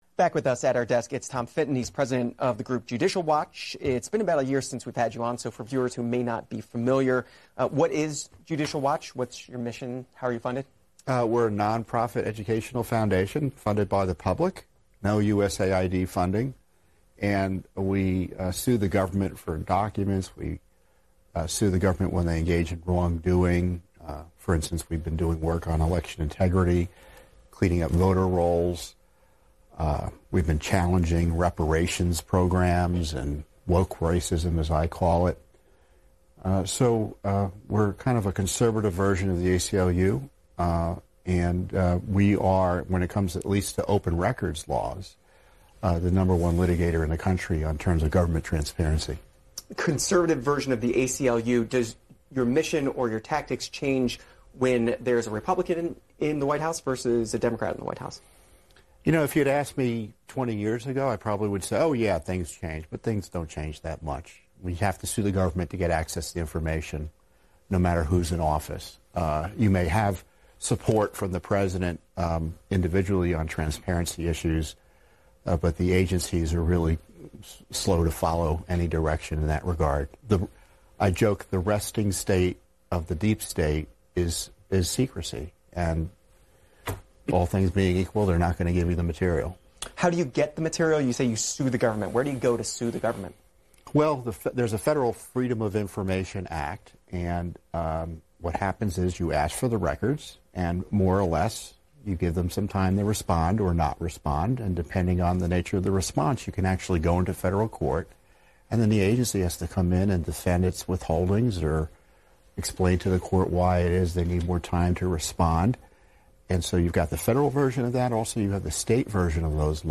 FITTON on C-SPAN: Congress Should Defund Corrupt Govt Agencies!
fitton-on-c-span-congress-should-defund-corrupt-govt-agencies.mp3